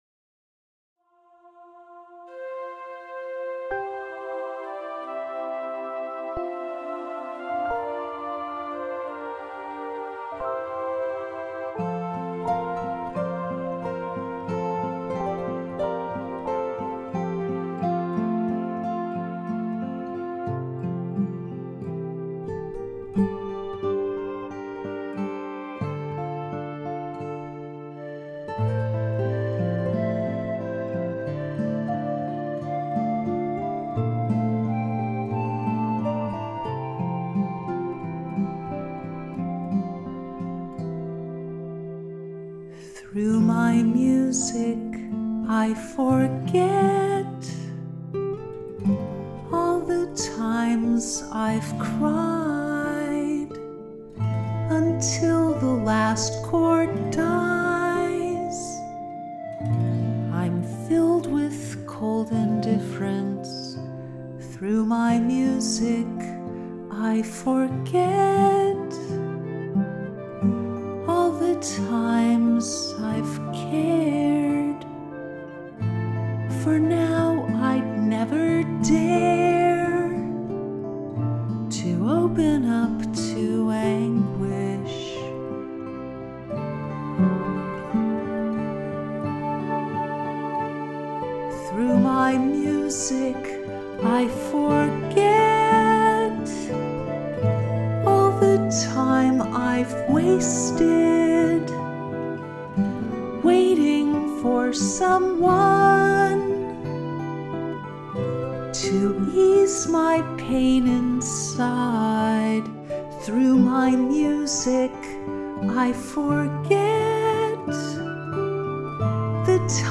Let these three heartfelt vocal songs inspire you with their touching messages.